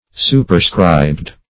Superscribe \Su`per*scribe"\, v. t. [imp. & p. p.